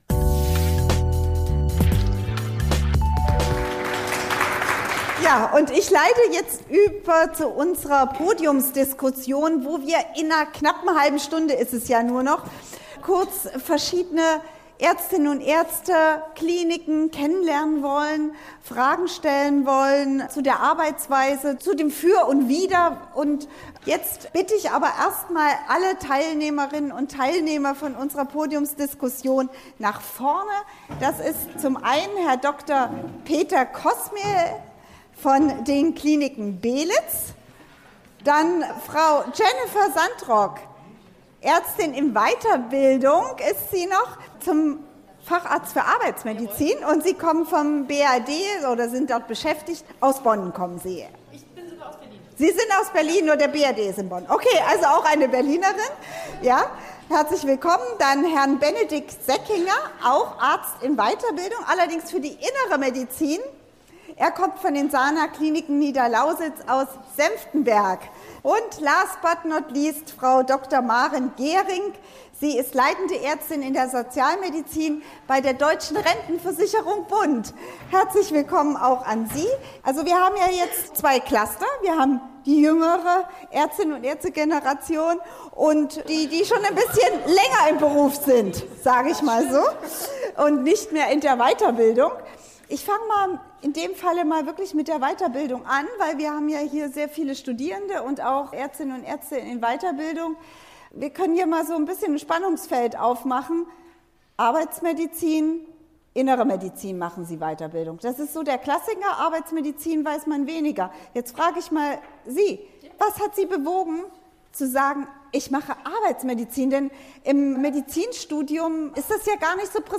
Präventiv, kurativ oder rehabilitativ arbeiten? In der Podiumsdiskussion stellen sich Ärztinnen und Ärzte vor, die in verschiedenen Fachgebieten arbeiten.